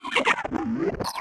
AFX_DROIDTALK_5_DFMG.WAV
Droid Talk 5